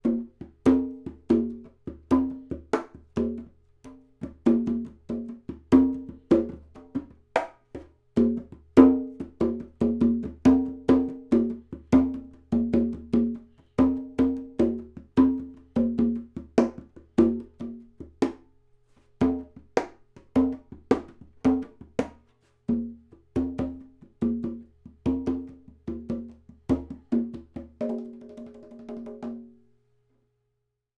Four couples of microphones have been placed in front of some instruments, in the same position and at the same time, to compare the results for stereo tracking.
Coincident cardiods
2 x 1/2″ diaphraghm condenser,coincident,130 degrees axes.
Coincident Congas
Coincident_Congas.wav